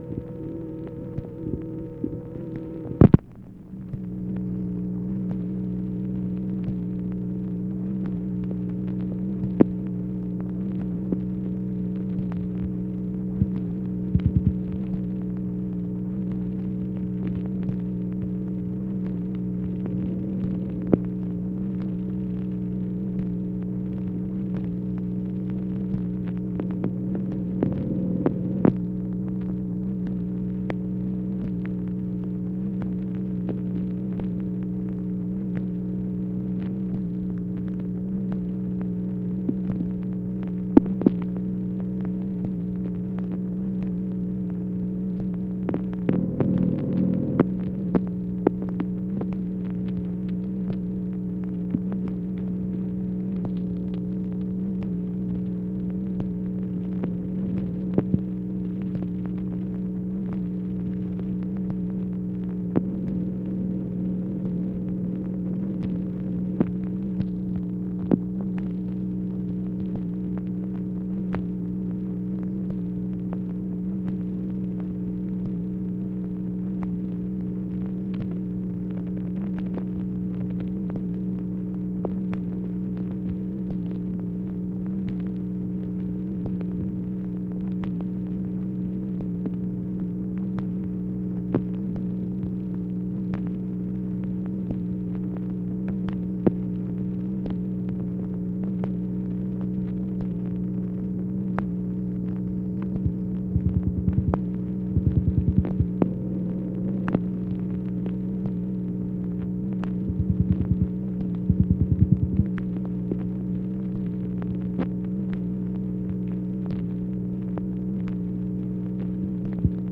MACHINE NOISE, January 31, 1964